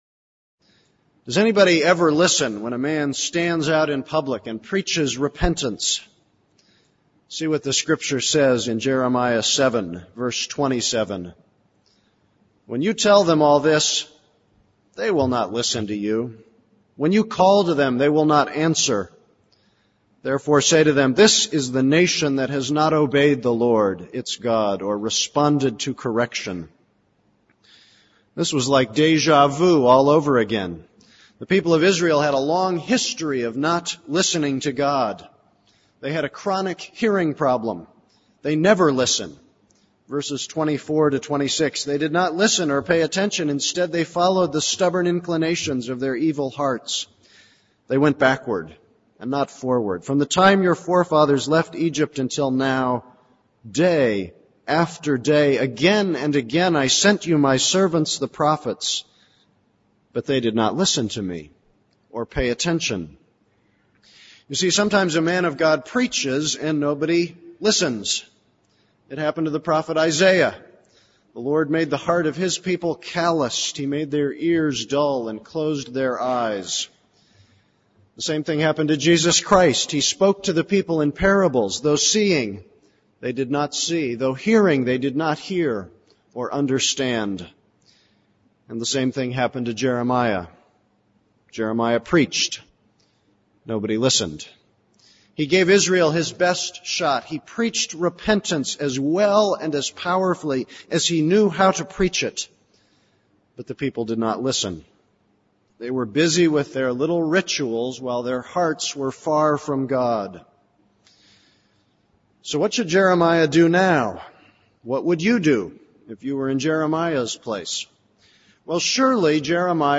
This is a sermon on Jeremiah 7:16-29.